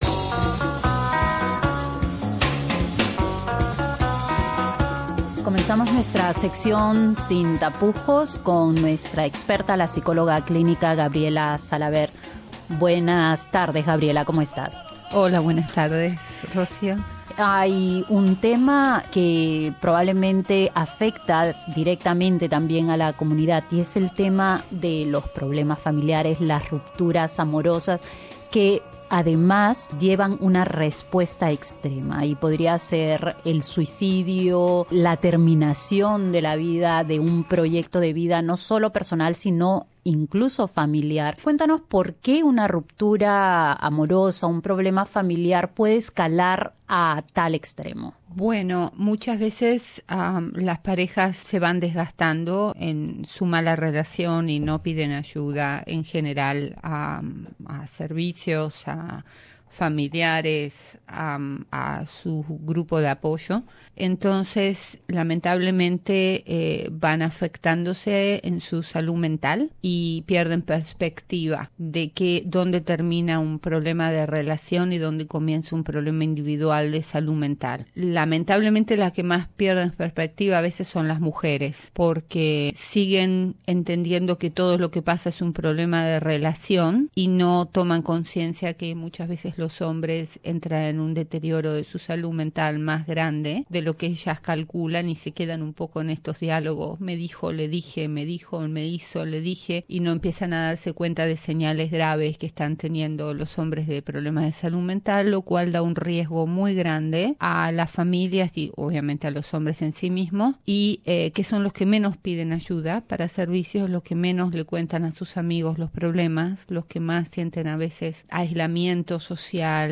Hablamos con la psicóloga clínica